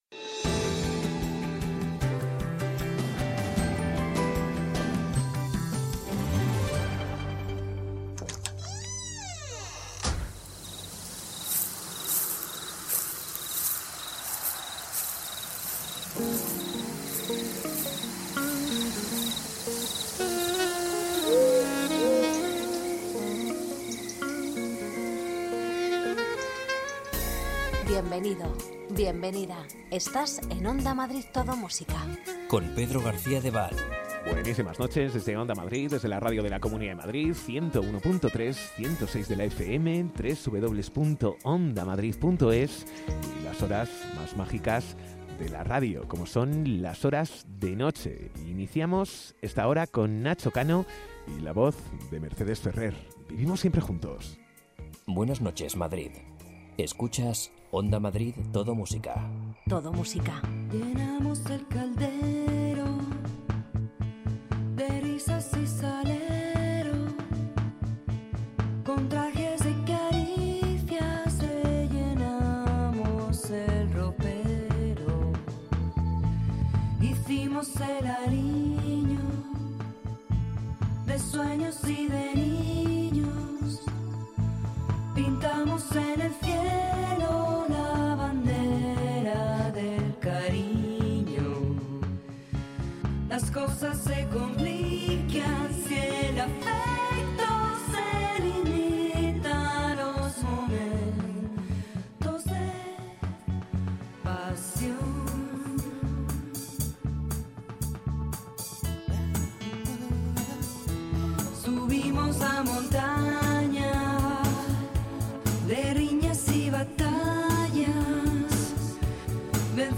Los mejores lentos
Ritmo tranquilo, sosegado y sin prisas.